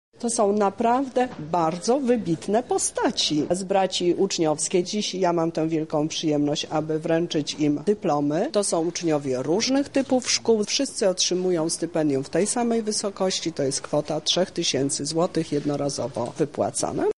O nagrodzie mówi Teresa Misiuk, Lubelski Kurator Oświaty: